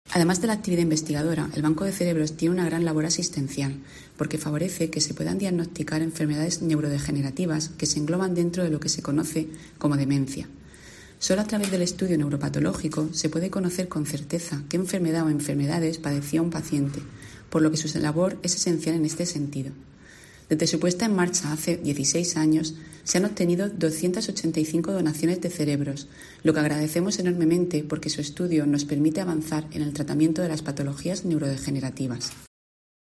Isabel Ayala, gerente del SMS